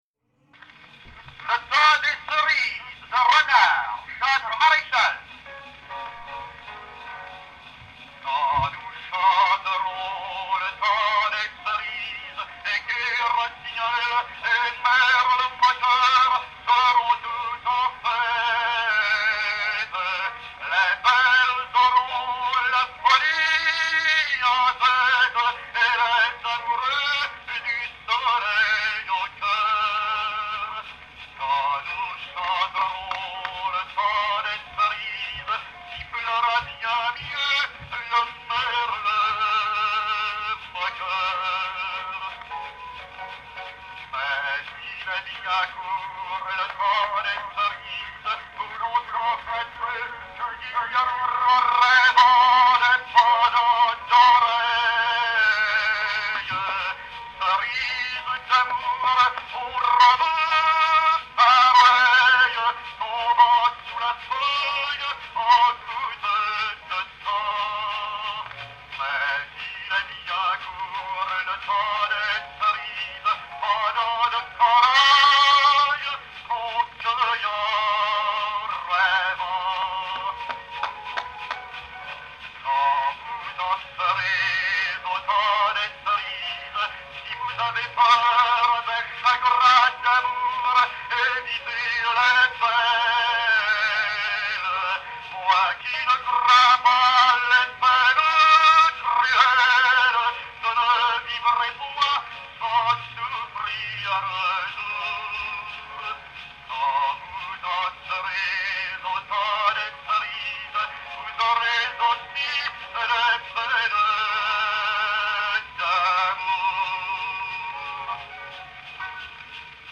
en cire brune